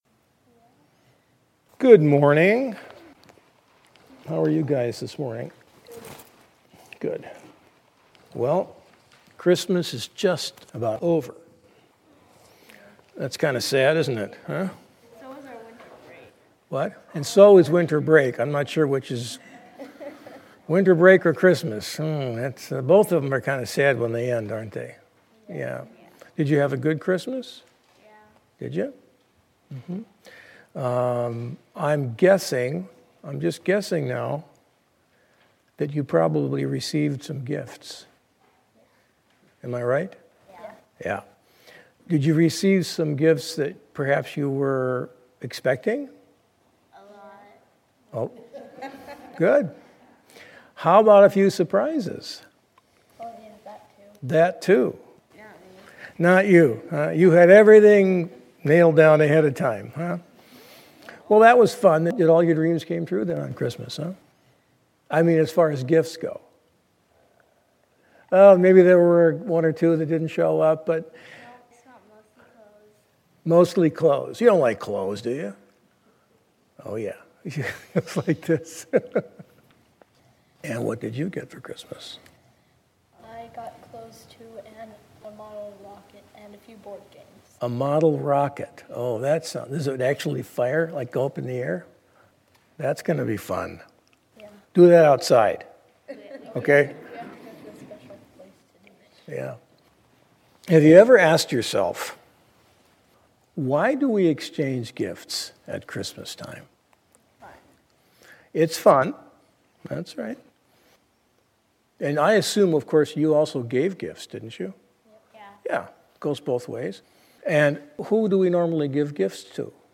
sermon-1-5-14.mp3